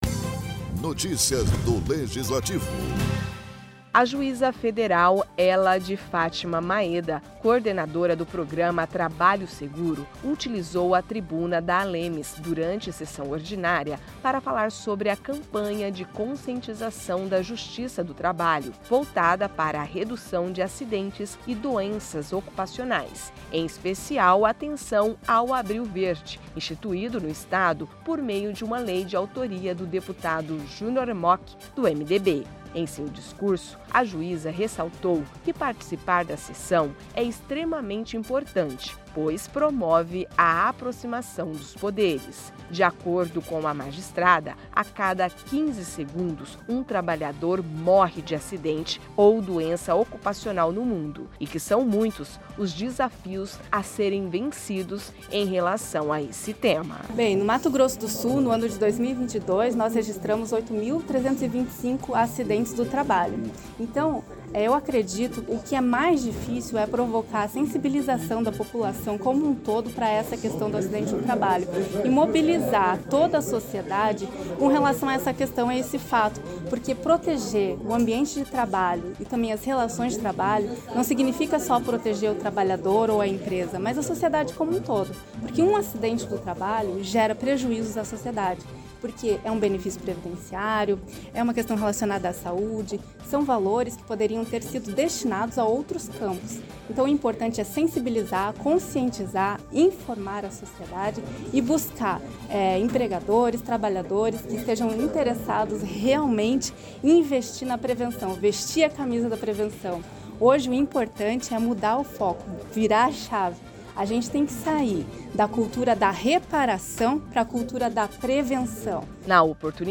A juíza federal, Hella de Fátima Maeda, coordenadora do Programa Trabalho Seguro (PTS), utilizou a tribuna da Assembleia Legislativa de Mato Grosso do Sul (ALEMS), durante sessão ordinária, para falar sobre a Campanha de Conscientização da Justiça do Trabalho voltada para a redução de acidentes e doenças ocupacionais, em especial atenção ao Abril Verde, instituído no Estado, por meio de uma Lei, de autoria do deputado Junior Mochi (MDB).